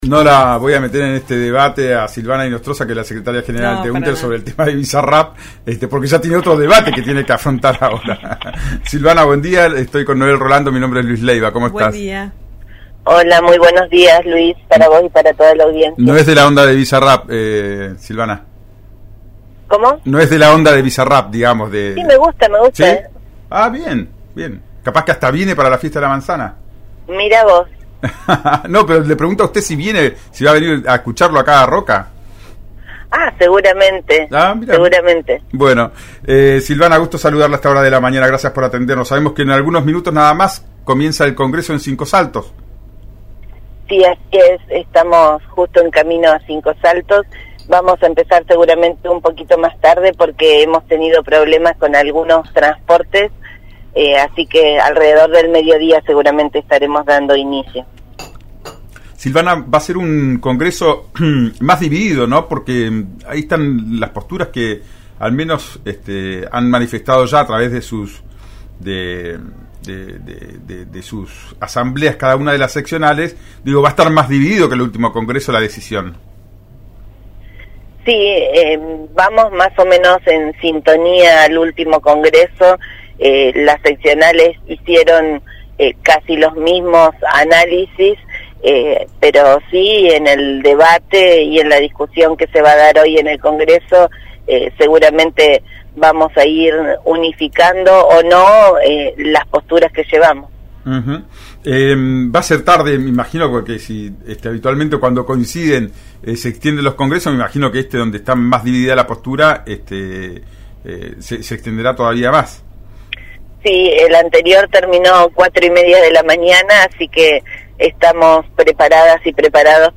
en «Ya es tiempo», por RÍO NEGRO RADIO: